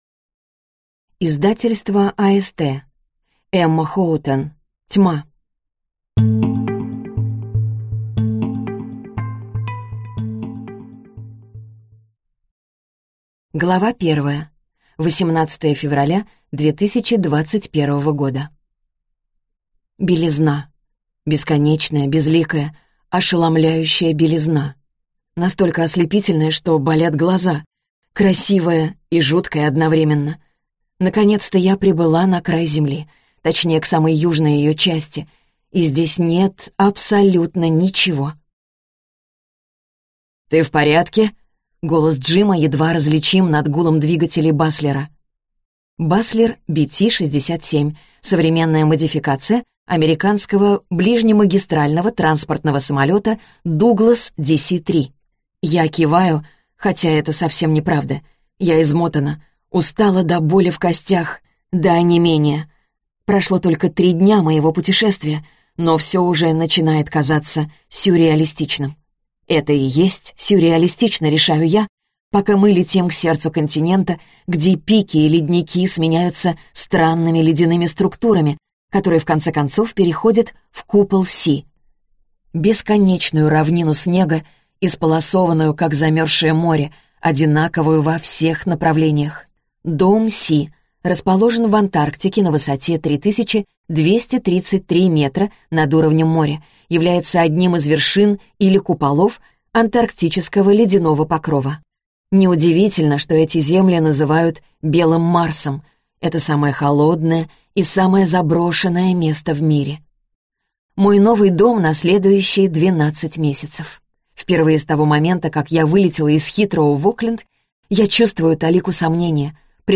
Аудиокнига Тьма | Библиотека аудиокниг